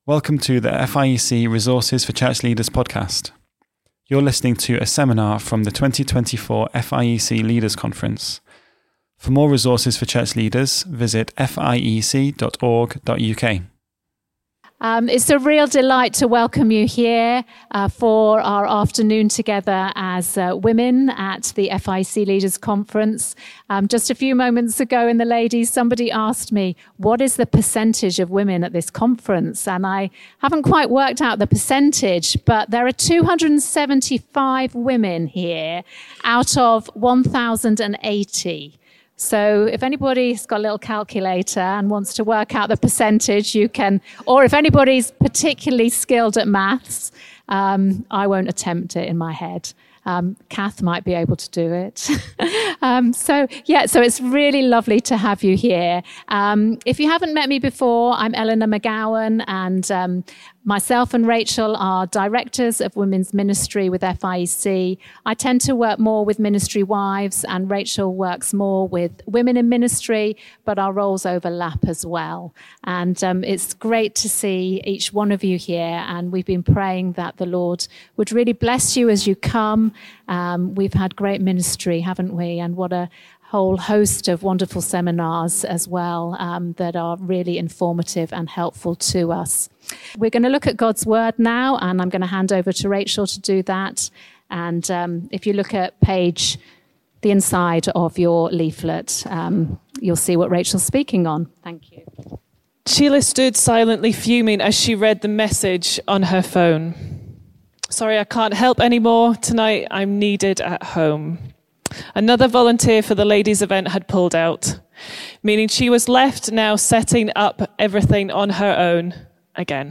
What should our priorities be in ministry and how are we we to respond to Jesus, the one who shepherds our souls? From the 2024 Leaders' Conference women's gathering.